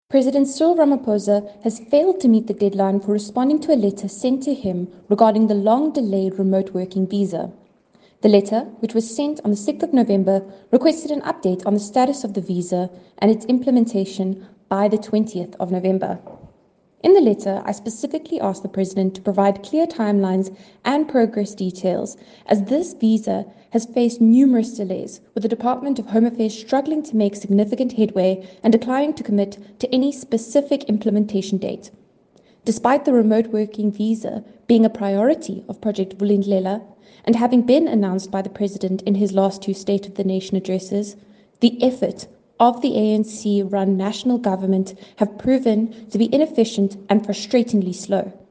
English audio by MPP Cayla Murray attached.